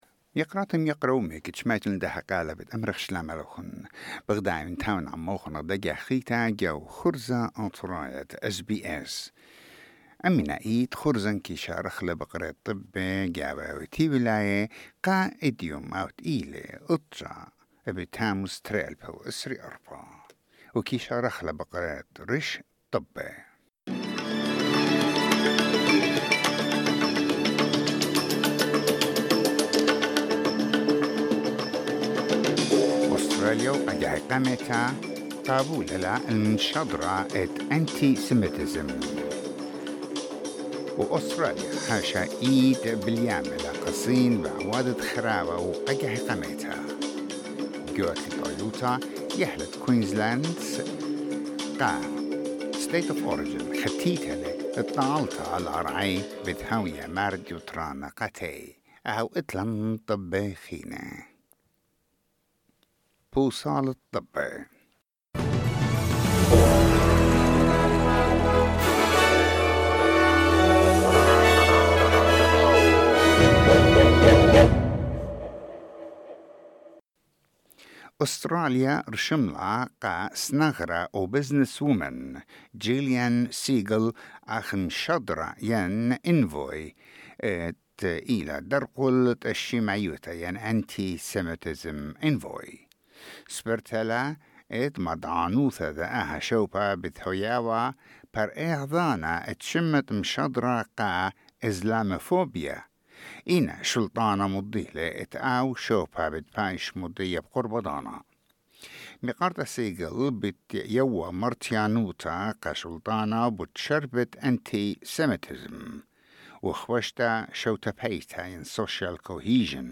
SBS Assyrian news bulletin: 9 July 2024